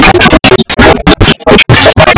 A rare interview